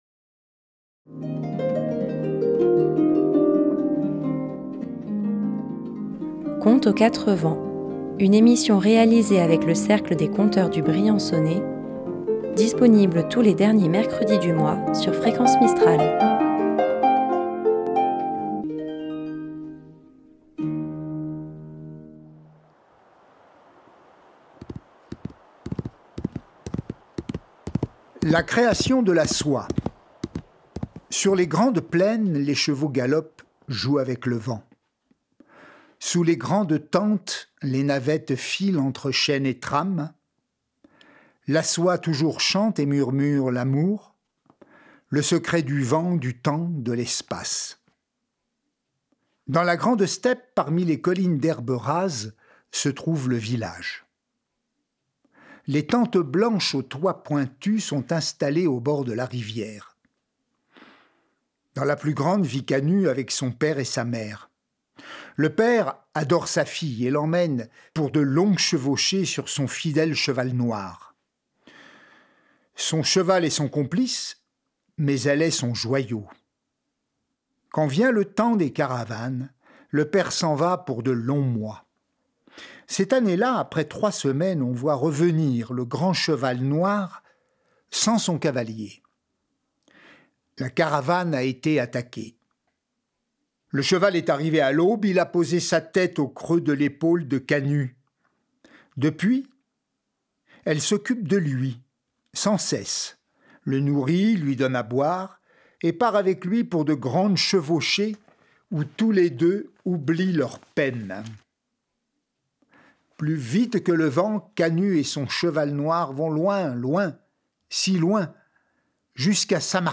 Tous les derniers mercredi du mois, à 18h11, retrouvez le Cercle des conteurs du Briançonnais pour une balade rêveuse.